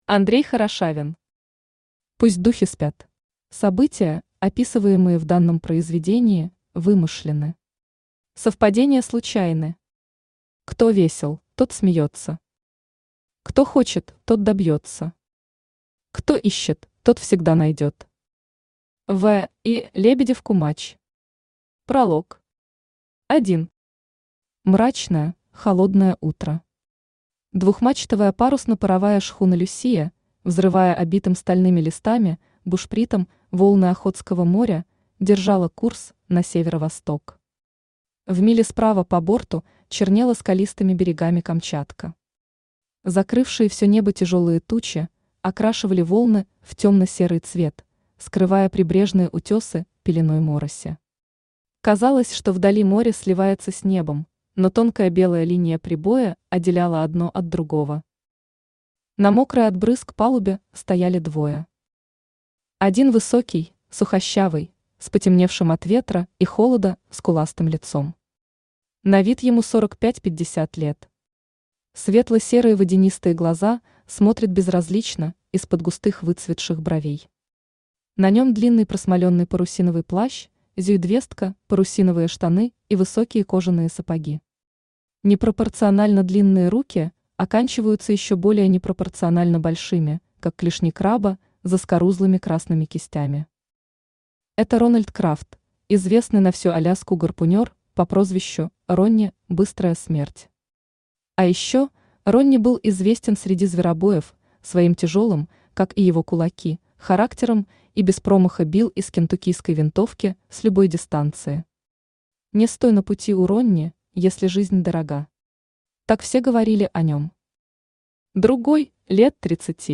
Аудиокнига Пусть духи спят | Библиотека аудиокниг
Aудиокнига Пусть духи спят Автор Андрей Хорошавин Читает аудиокнигу Авточтец ЛитРес.